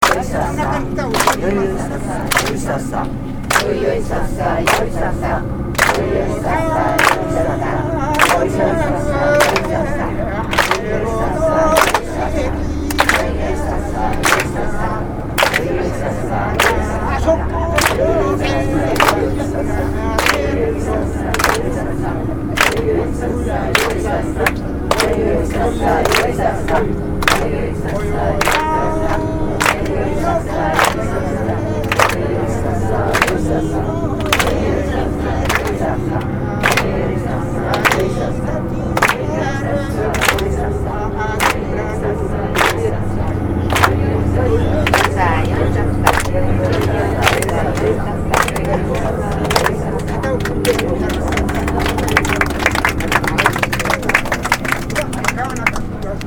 ・　旅先で得た音楽や音をアップしました。
◎　高千穂交通ガイドの唄　（夜神楽せり唄）